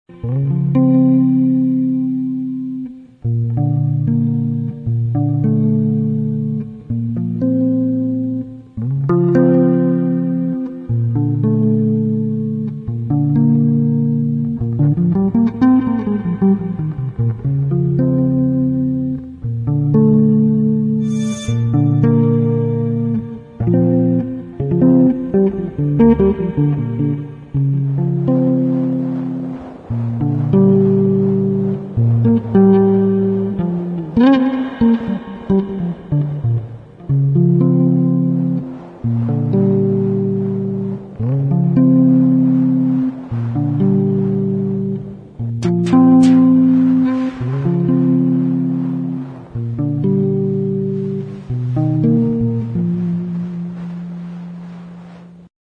[ DEEP HOUSE / JAZZ / CROSSOVER ]